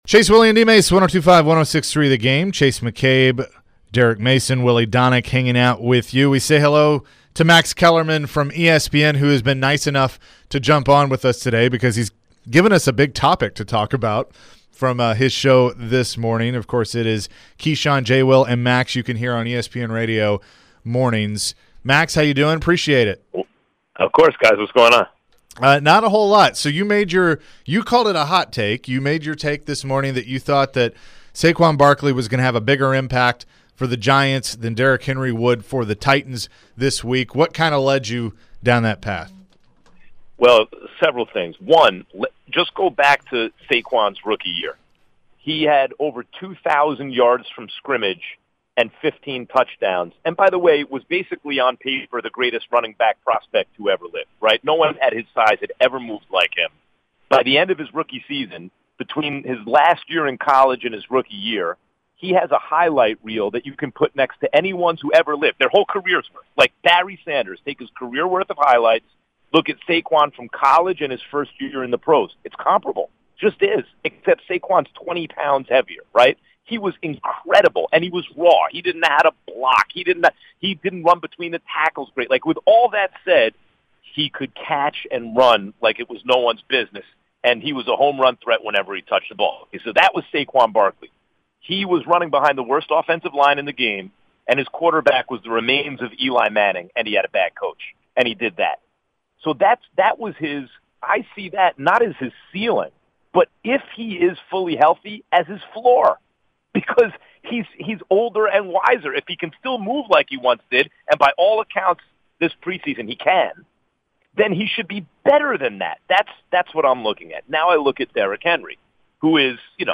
Max Kellerman interview (9-9-22)